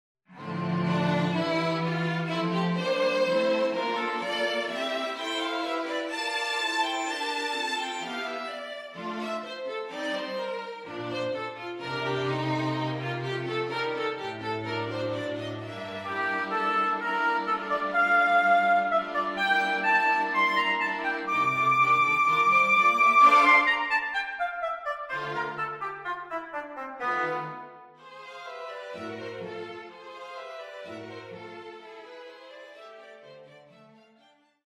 closing bars of I Allegro:
A charming English neoclassical work in three movements: